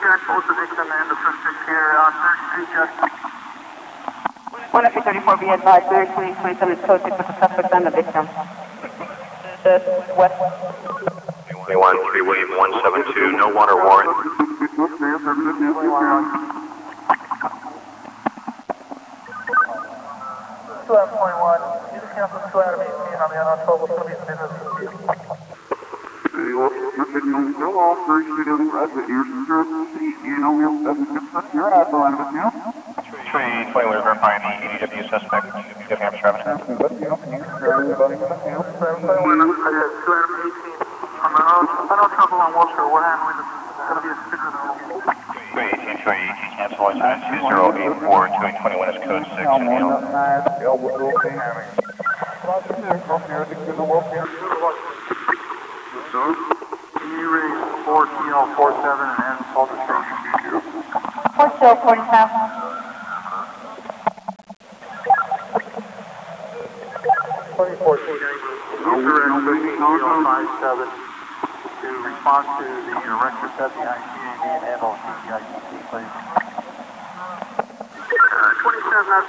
policeradio.wav